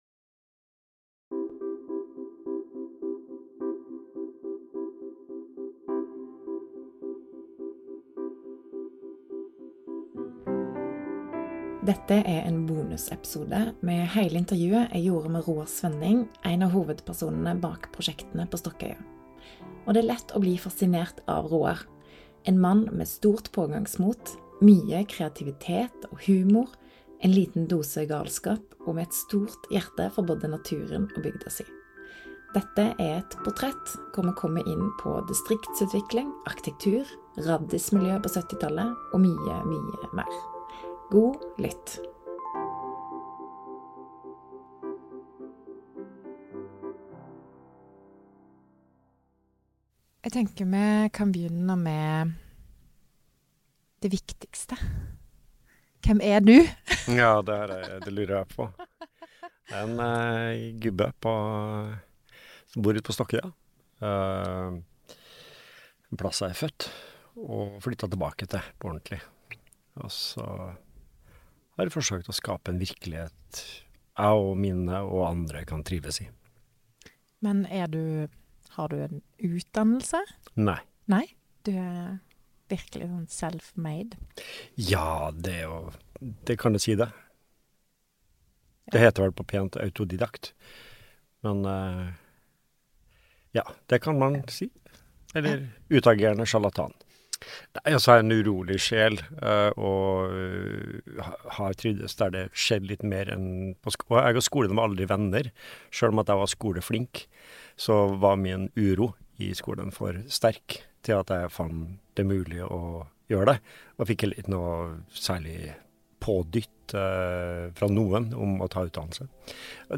Podkasten er et samarbeid mellom Arkitektbedriftene Norge, Afag og Norsk Eiendom - og en del av Arkitektur skaper verdi.